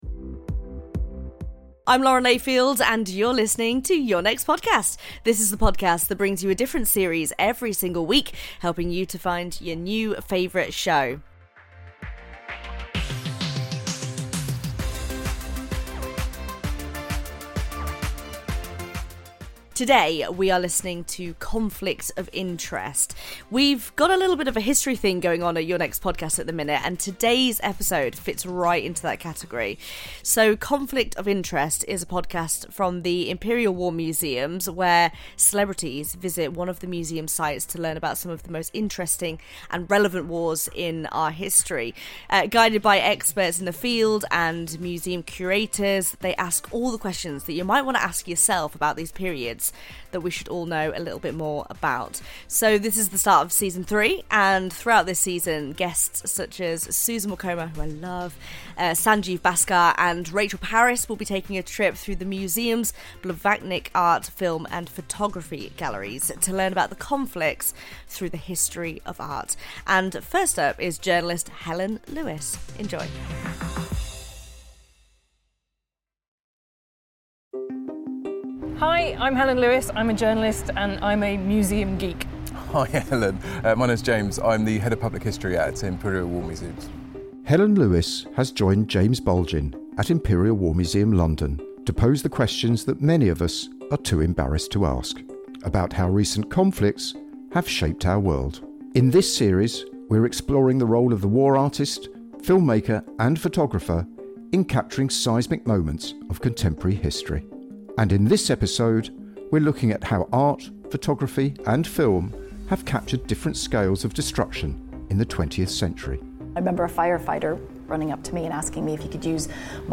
Lauren Layfield introduces Conflict of Interest on the series recommendation show Your Next Podcast.